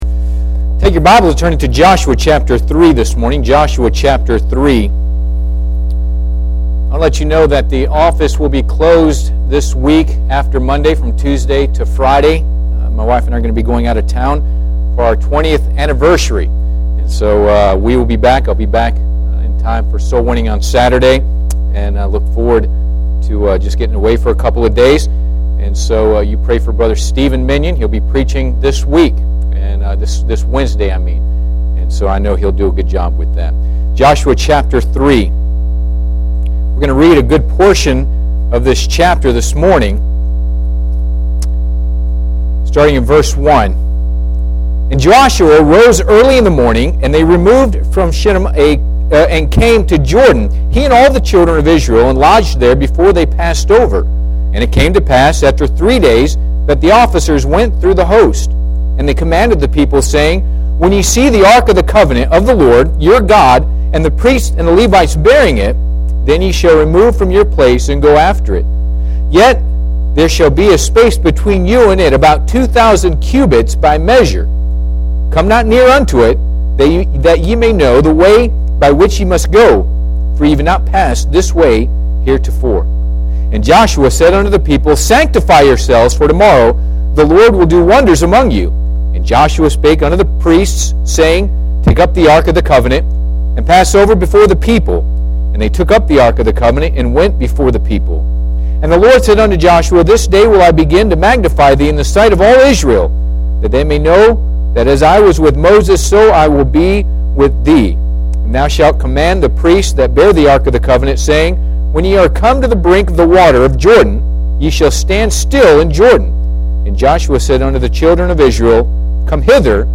Passage: Joshua 3:1-13 Service Type: Sunday AM